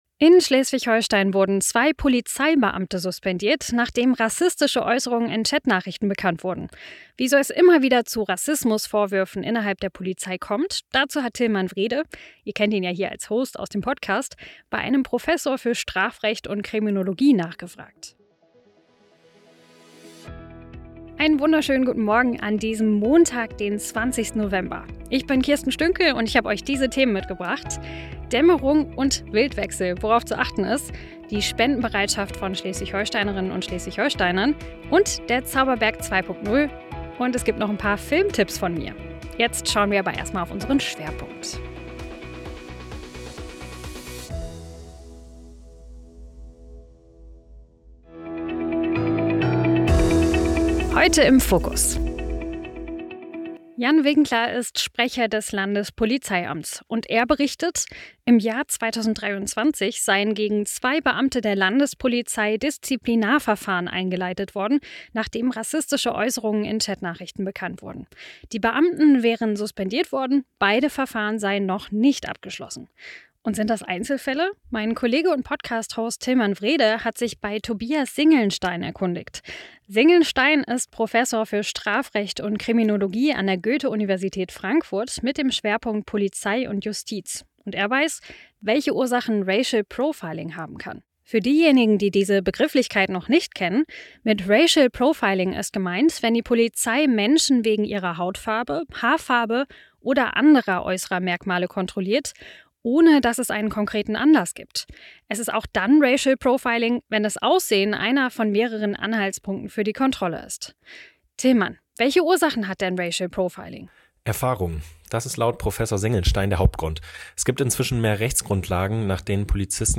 Nachrichten
innerhalb der Polizei kommt, erklärt ein Professor für Strafrecht